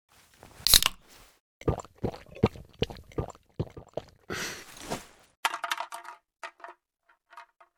energy_drink.ogg